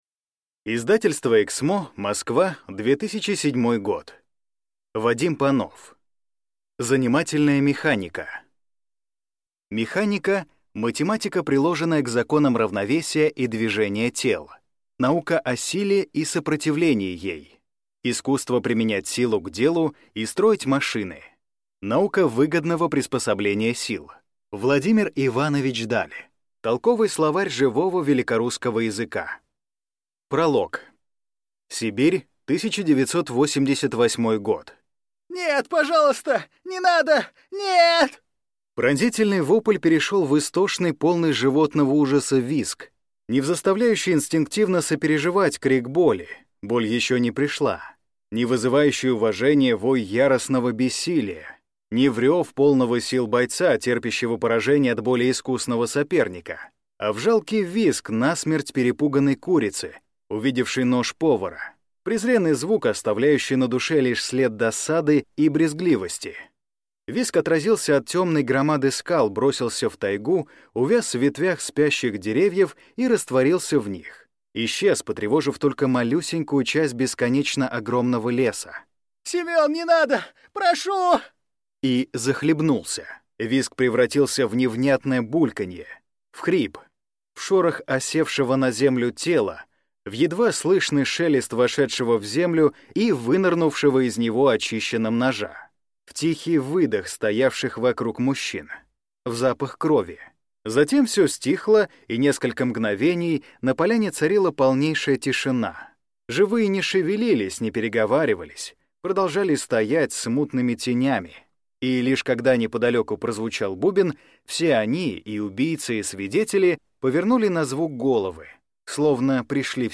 Аудиокнига Занимательная механика | Библиотека аудиокниг